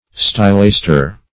Search Result for " stylaster" : The Collaborative International Dictionary of English v.0.48: Stylaster \Sty*las"ter\, n. [NL., from Gr. sty^los pillar + 'asth`r star.]